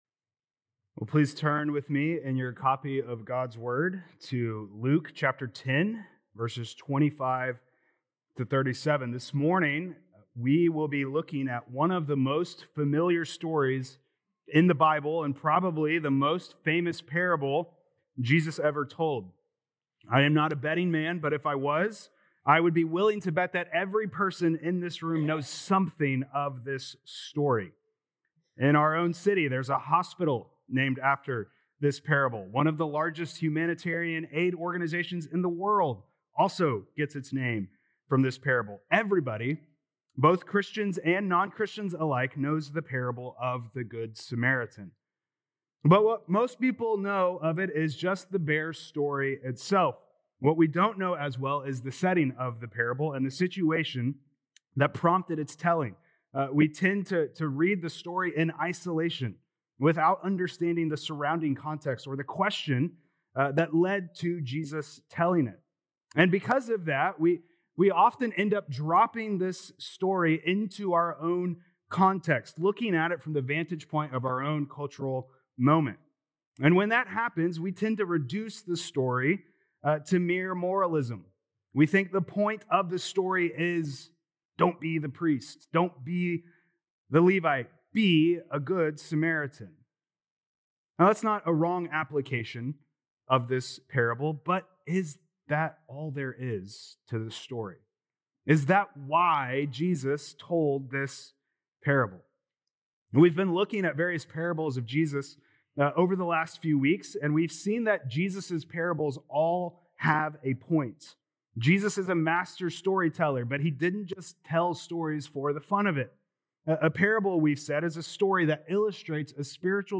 Feb 15th Sermon